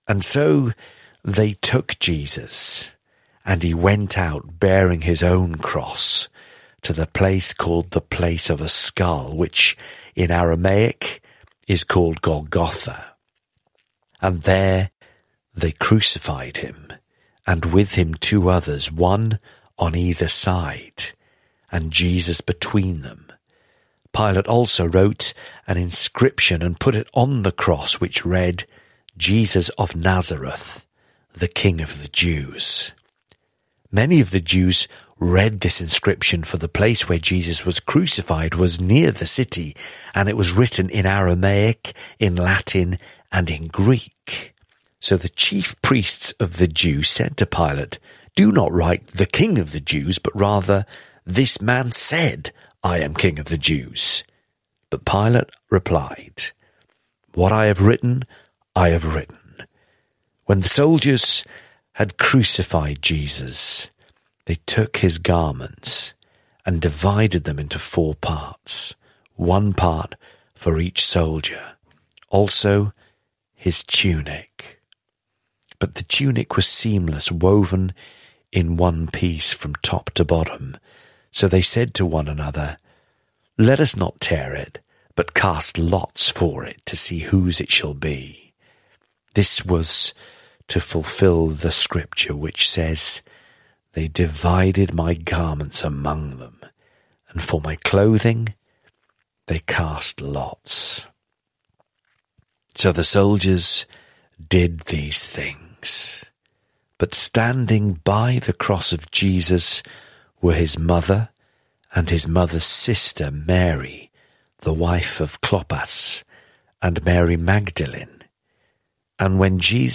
teachers on the daily Bible audio commentary